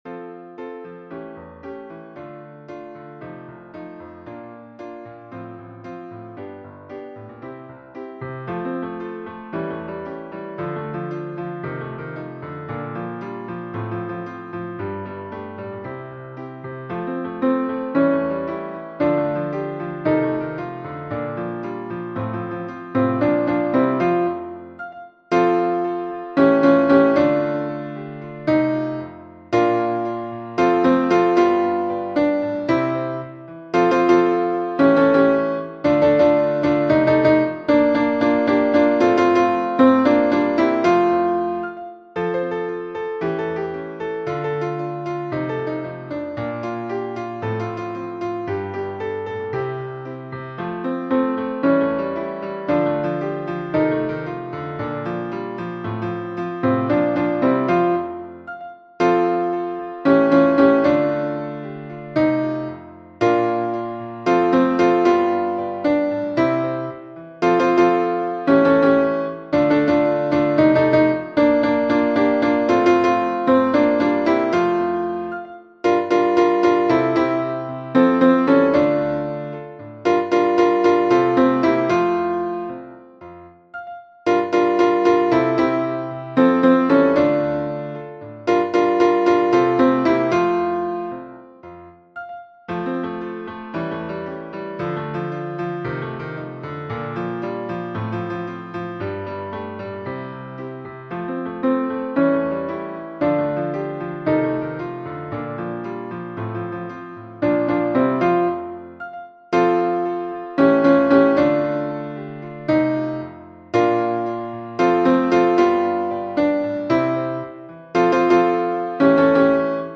MP3 version piano
Alto